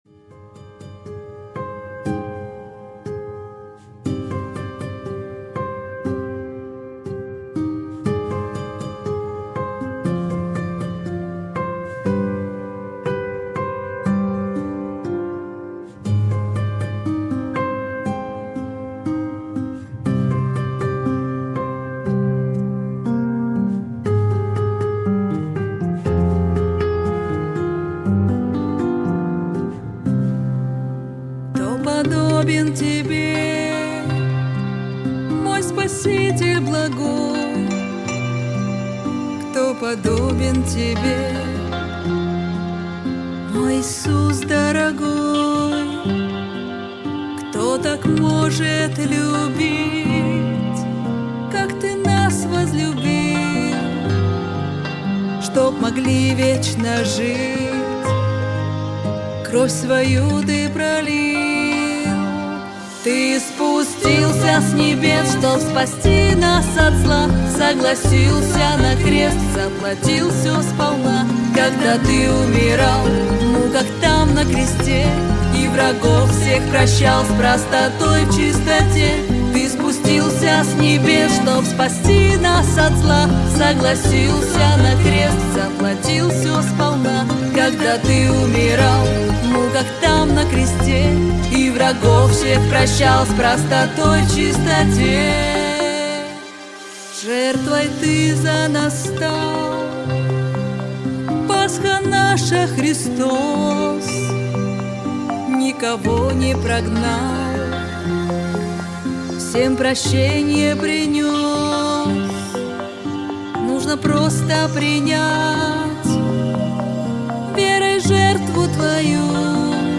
567 просмотров 210 прослушиваний 13 скачиваний BPM: 120